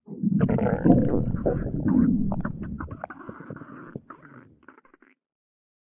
Compresses and normalizes vore sounds 2021-07-18 06:21:01 +00:00 50 KiB Raw History
digest_12.ogg